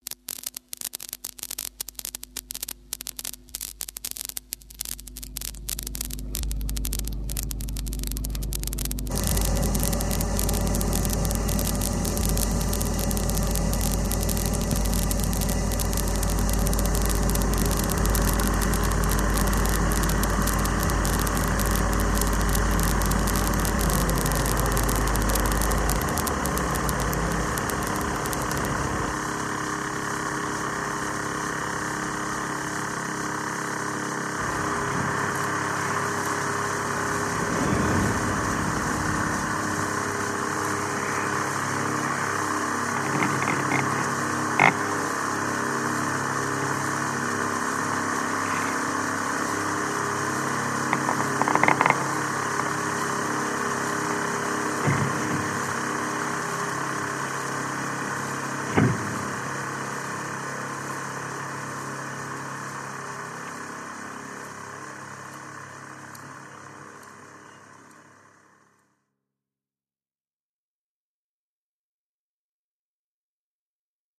tape_reaction knüpft an diese Geschichte an und setzt ein Tonbandgerät in den Mittelpunkt einer Performance.
Aus der ursprünglichen Funktion des Gerätes voraufgezeichnete Klänge wiederzugeben, wird es selbst zum Klanglieferanten. Betriebsgeräusche und Präparierungen, wie die an der mikrofonierten Gehäuseoberfläche fixierten Motoren und Metallgegenstände, bringen das üblicherweise stumme Eigenleben der Maschine in das Klanggeschehen ein.
04-tape_reaction_p1.mp3